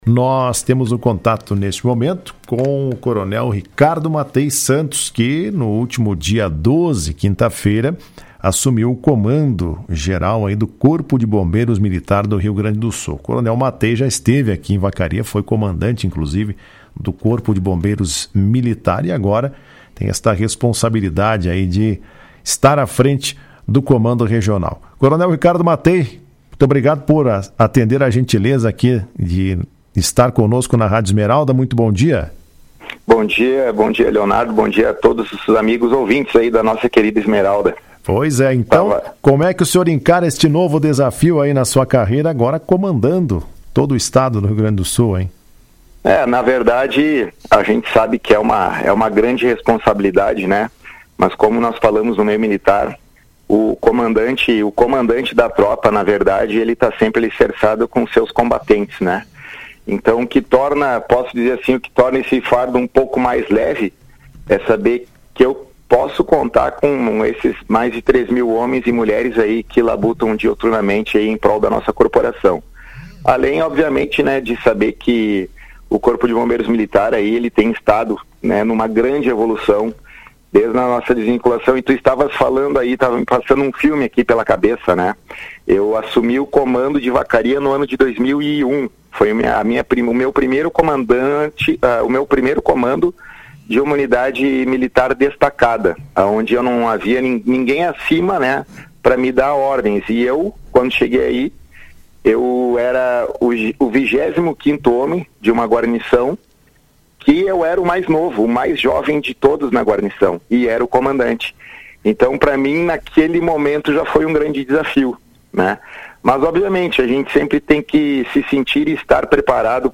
Em entrevista à Rádio Esmeralda, o Coronel Ricardo Mattei disse que seu maior desafio a frente da corporação será o comando de mais de 3.000 militares.